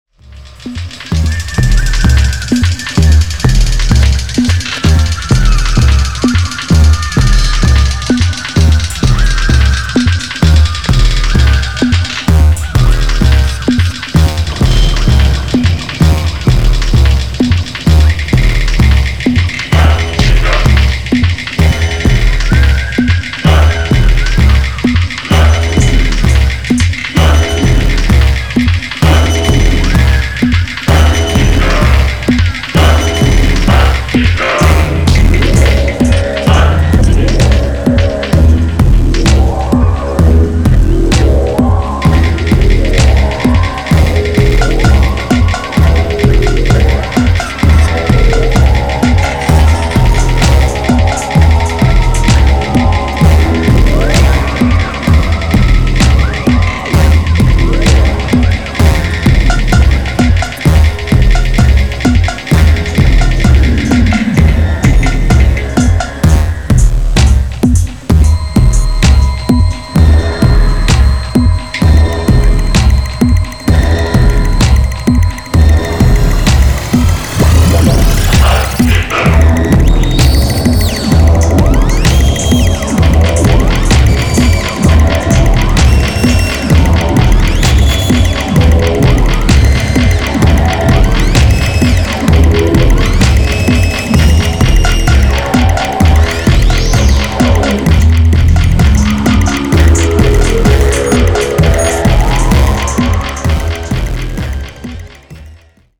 ストーンドなグルーヴやモジュラーシンセの歪みでポスト・パンク感を追求した